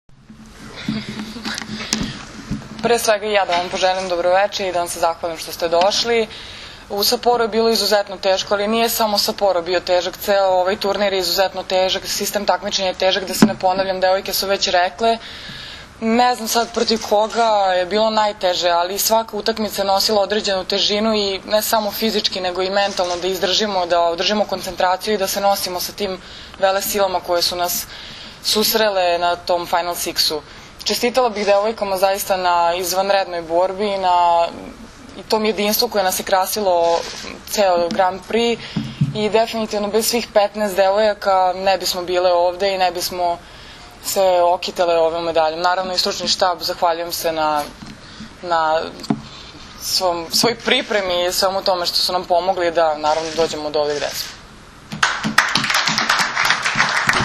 U salonu „Beograd“ aerodroma „Nikola Tesla“ održana je konferencija za novinare, na kojoj su se predstavnicima medija obratili Maja Ognjenović, Brankica Mihajlović, Milena Rašić, Jovana Brakočević i Zoran Terzić.
IZJAVA JOVANE BRAKOČEVIĆ